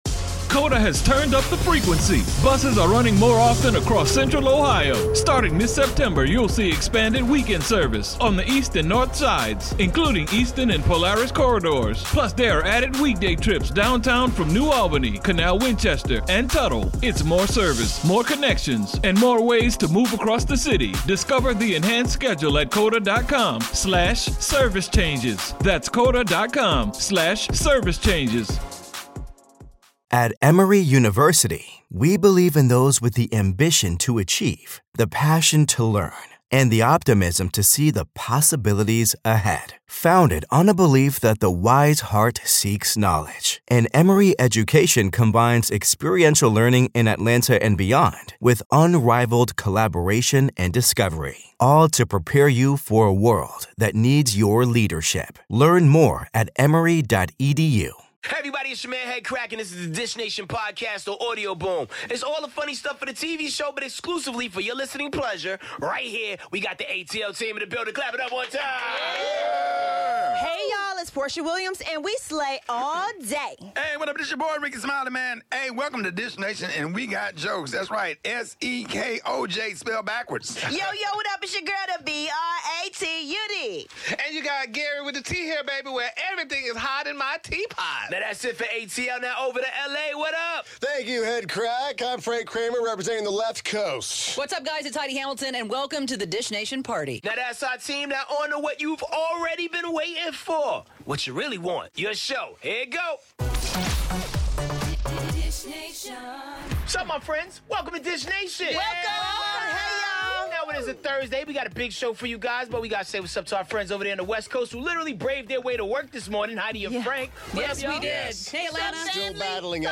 Tyrese is dishing in studio with us and look at the best moments from last night's 'Empire' and 'Star.' Plus all the latest on Taylor Swift, Halle Berry, Gwyneth Paltrow, Katie Cassidy and much more!